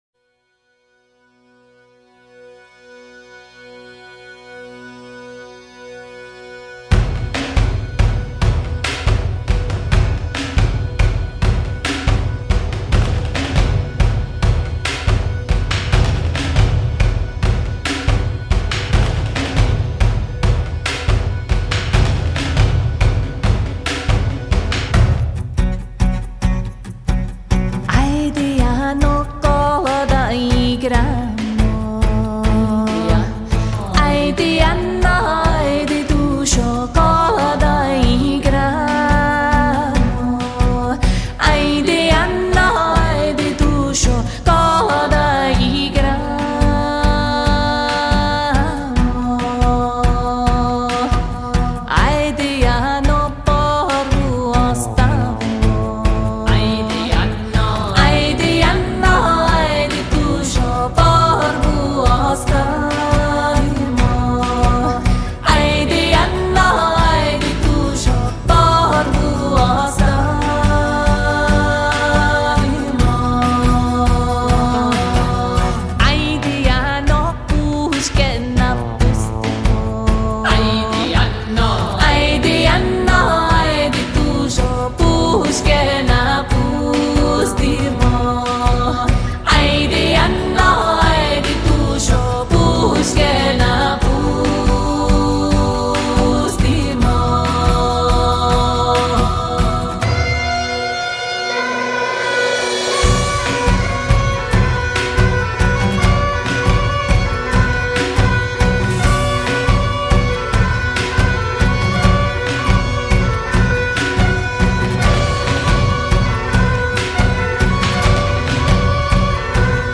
改编自克尔特传统民谣